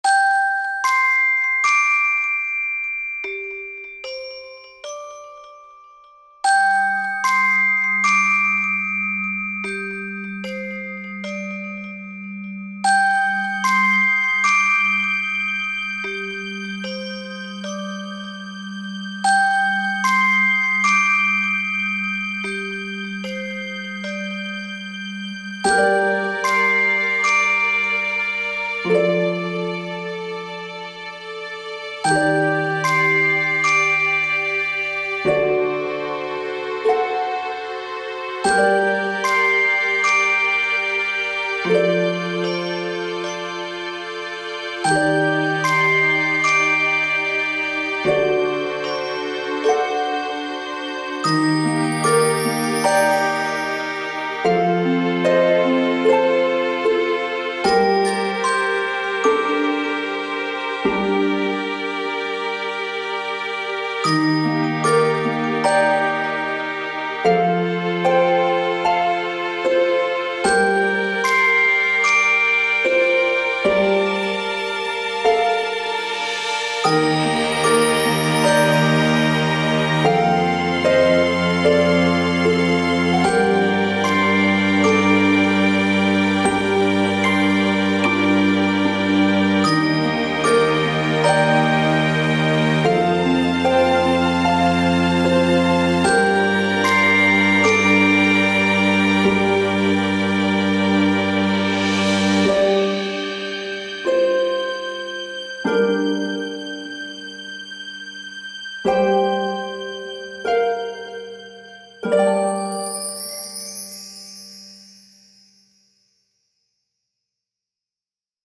単曲＆ループ可。グロッケンとハープが中心。優しげで、淡く神秘的なイメージ。